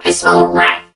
mech_crow_start_02.ogg